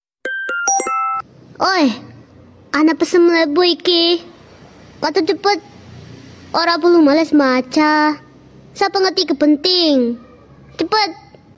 Nada Notifikasi Versi Jawa
Kategori: Nada dering
nada-notifikasi-versi-jawa-id-www_tiengdong_com.mp3